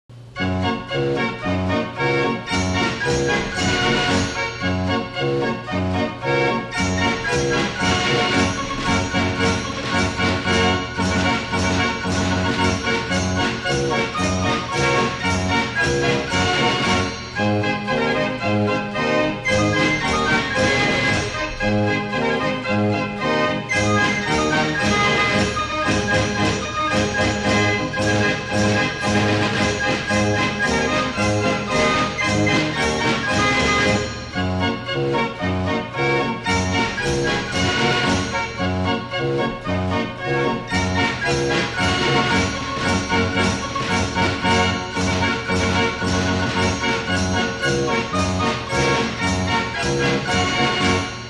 52 KEY FRENCH GASPARINI FAIR ORGAN